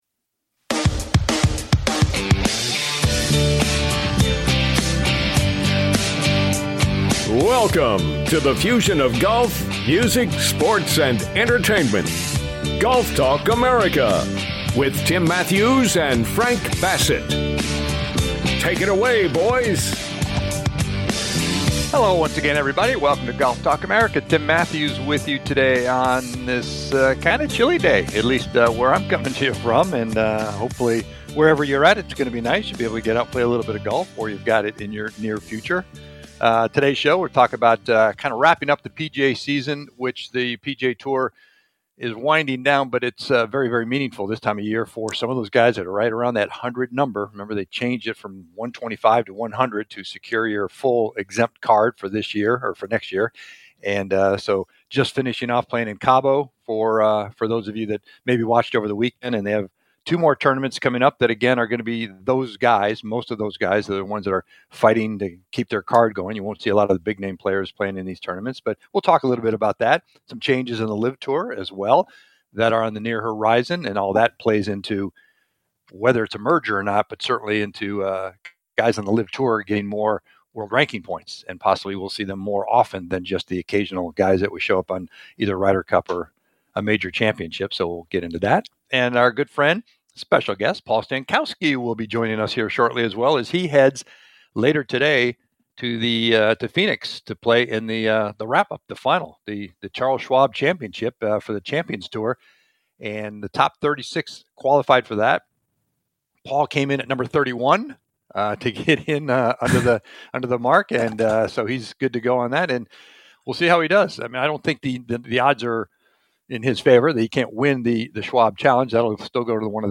PGA TOUR CHAMPION PAUL STANKOWSKI JOINS THE SHOW